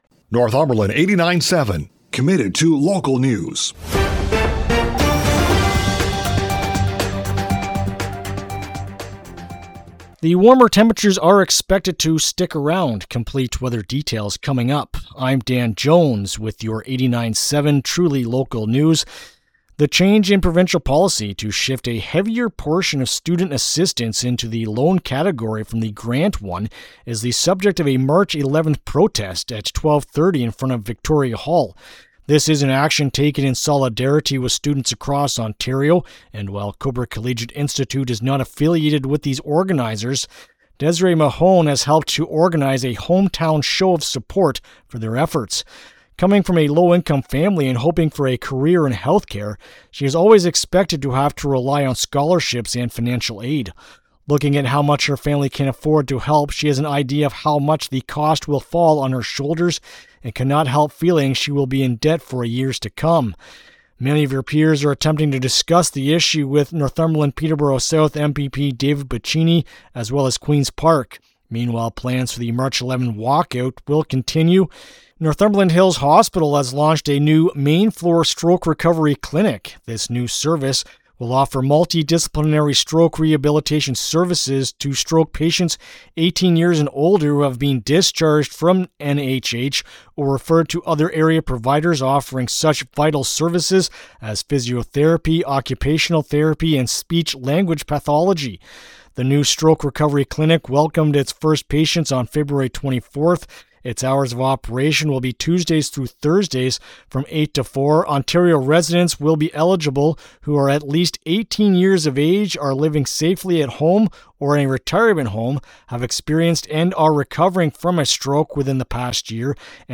Tuesday-March-3-AM-News-1.mp3